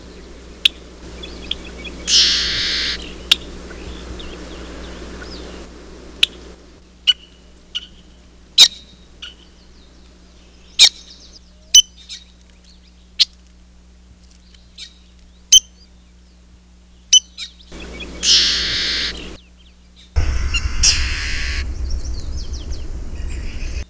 "Yellow-shouldered Blackbird"
Angelaius xanthomus